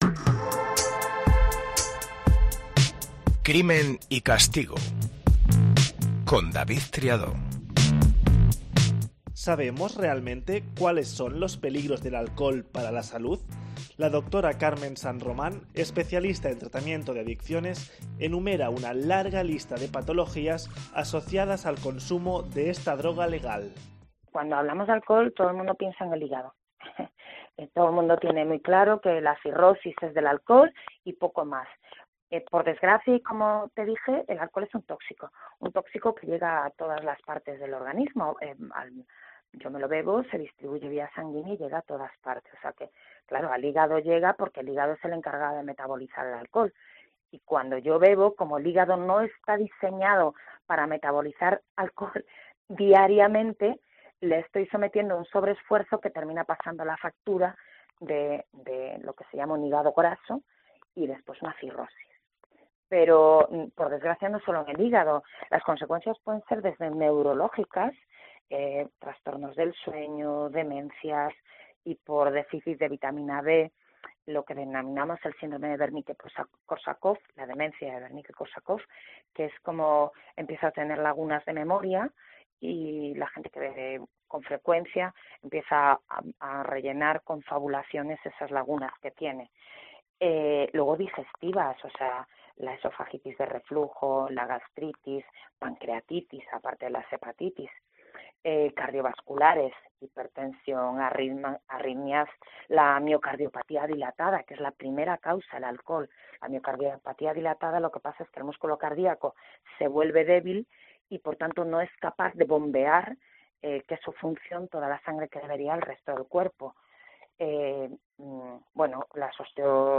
Lo hace en un formato dinámico, fresco, cercano, y con entrevistas a los implicados e intervenciones de expertos.